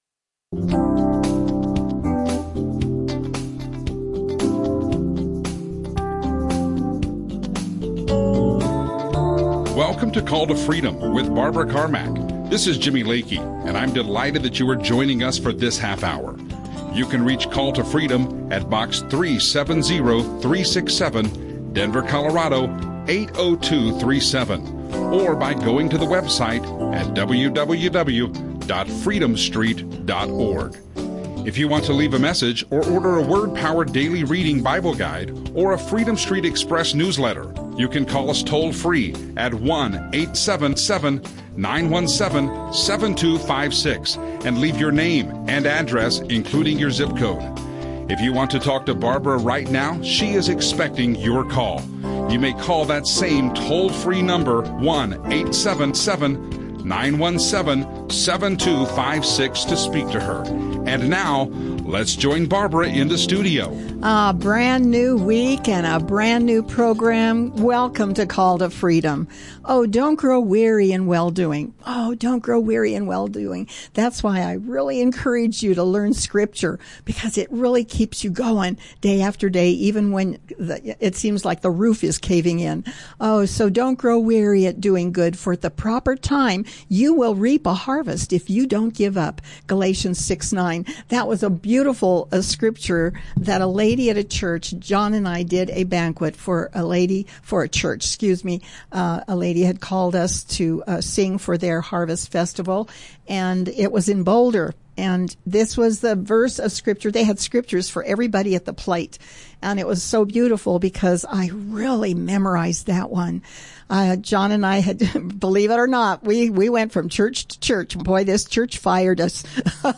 Christian talk show